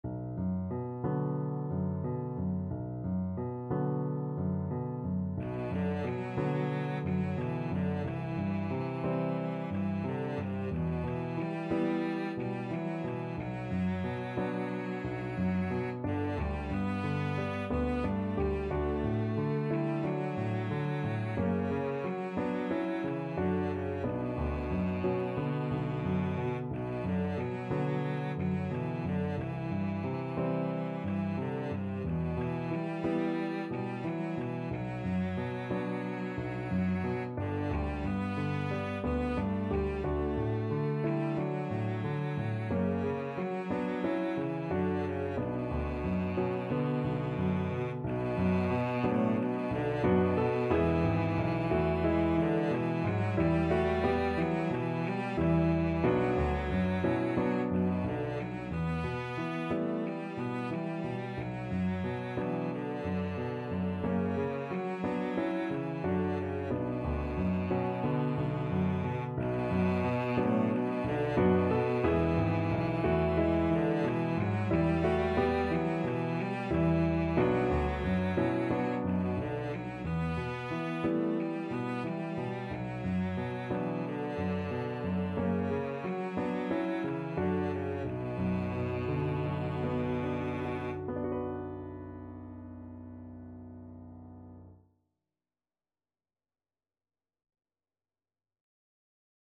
Cello version
4/4 (View more 4/4 Music)
Gently Flowing =c.90
Classical (View more Classical Cello Music)